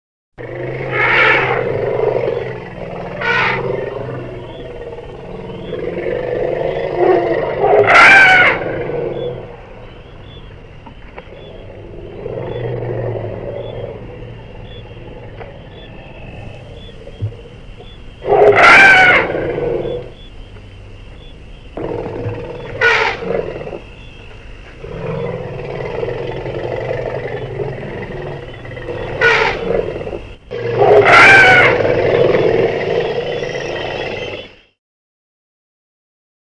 L’éléphant | Université populaire de la biosphère
il baréte, barrit, barronne
elephant.mp3